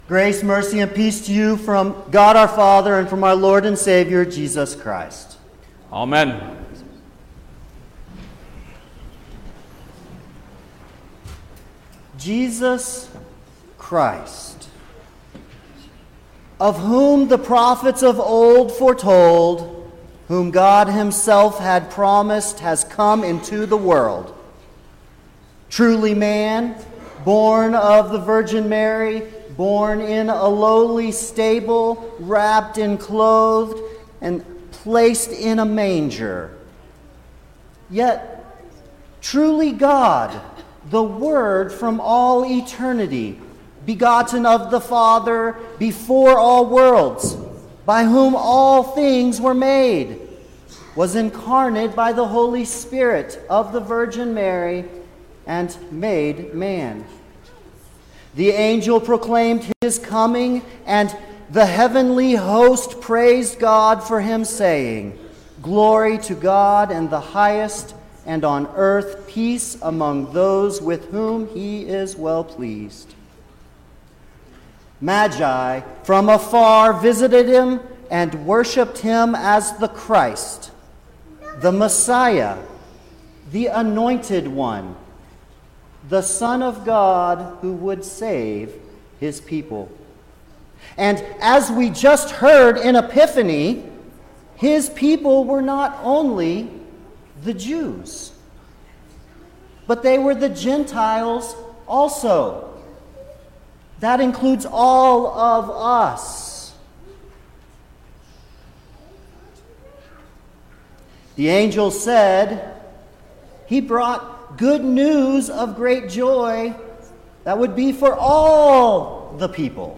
January-9_2022_The-Baptism-of-Our-Lord_Sermon_Stereo.mp3